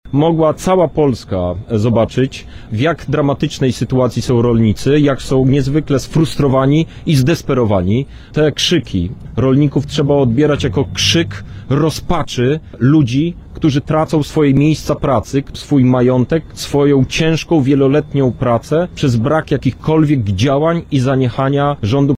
W latach 2014-2020 Polska otrzymała w ramach Wspólnej Polityki Rolnej 32,1 mld euro, to na lata 2021-2027 dostaniemy mniej – 30,5 mld euro – podkreślił podczas dzisiejszej (20.03) konferencji Krzysztof Hetman, poseł do Parlamentu Europejskiego.